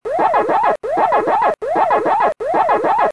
add sound effect Download to folder hw/PacmanApp/res/raw the following file: wakawaka.wav (right-click, Save As...)
wakawaka.wav